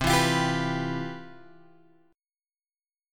C Major 11th